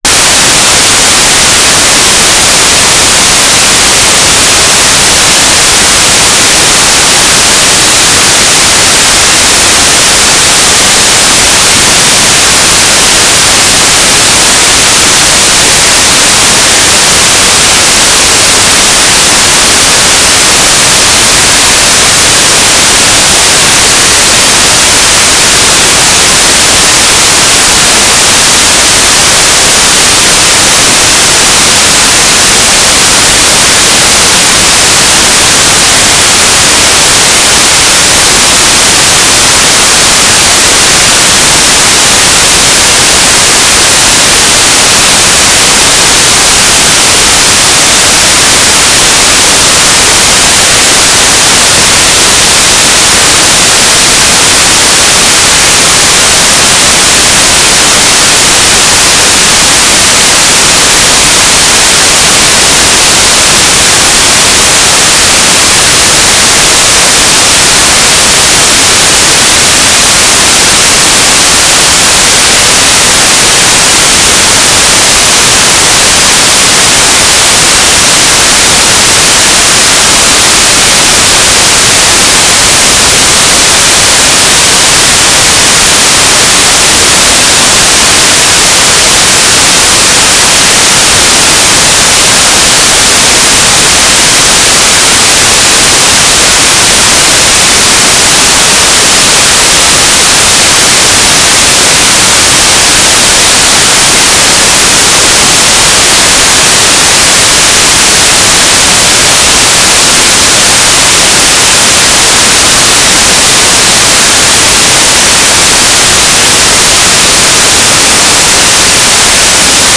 "transmitter_description": "Mode U - Transmitter",